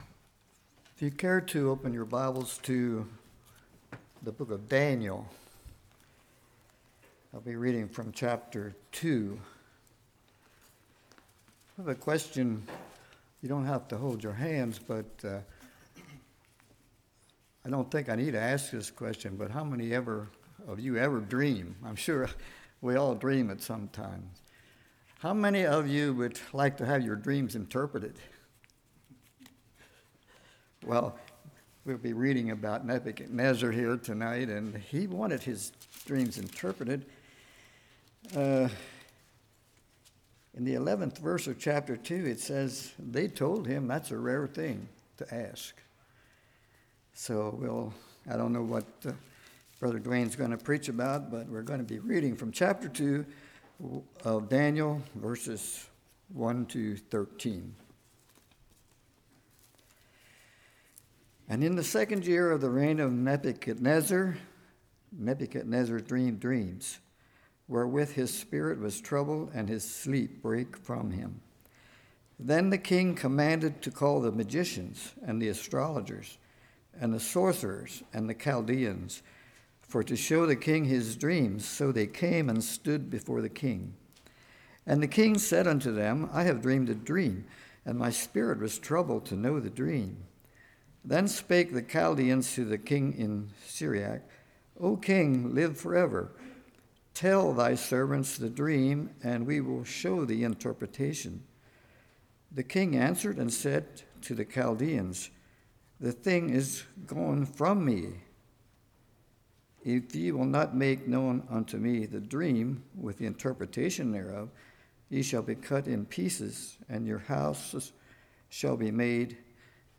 Passage: Daniel 2:1-13 Service Type: Evening